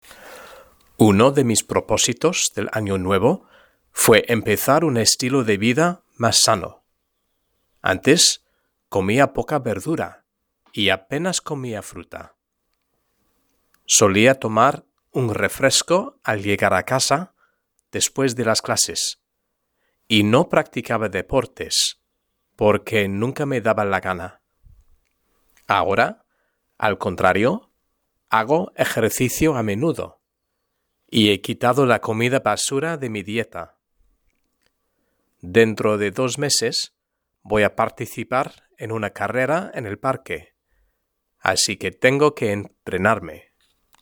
Lectura en voz alta: 1.2 La vida sana (H)